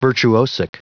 Prononciation du mot : virtuosic
virtuosic.wav